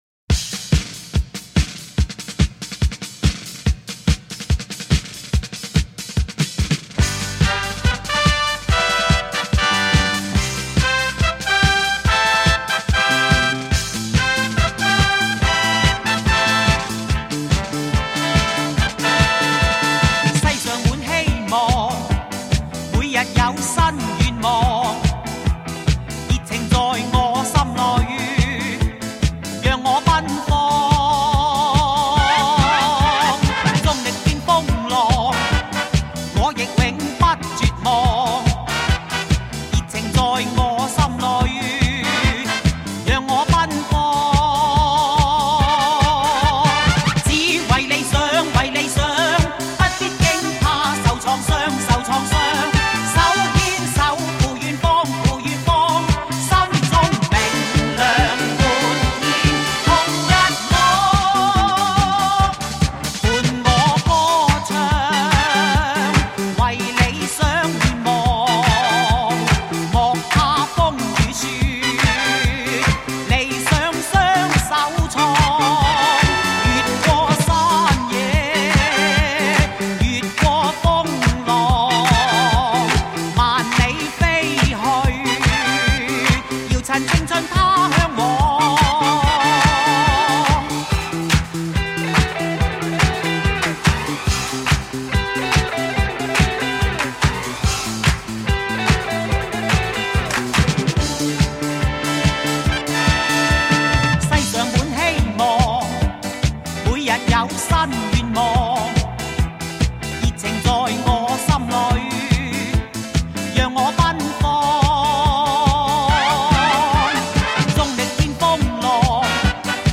纯正的发烧音色 细腻的音乐质感
人声醇厚突出 靓声典范 非凡体验
无损音乐